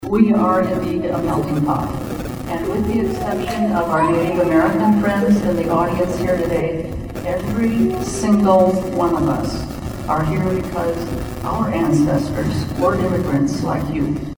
Fort Pierre Mayor Gloria Hanson was one of the speakers.
Fort Pierre Mayor Gloria Hanson speaks at a citizenship ceremony Nov. 4, 2022, at Parkview Auditorium in Fort Pierre.